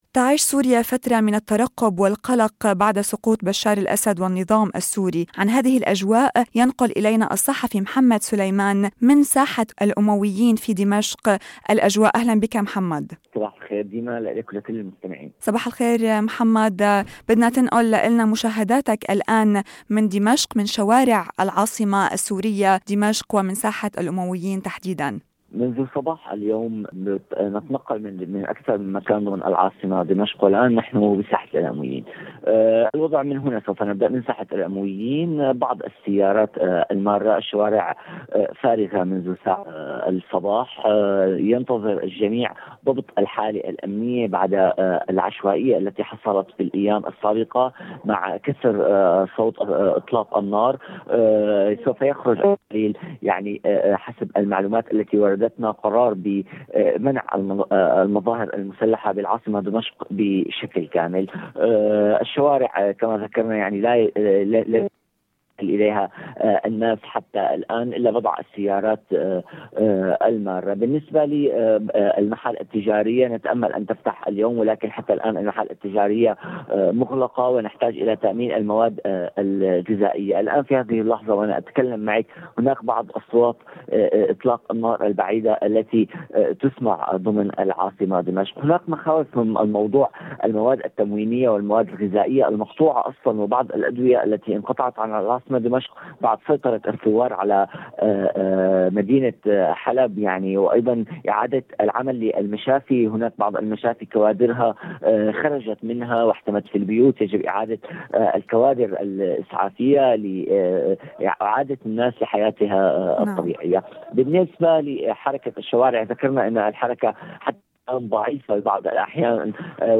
دمشق بين الفرح والترقب: مشاهدات حيّة لصحفي سوري من ساحة الأمويين
لقاء حصري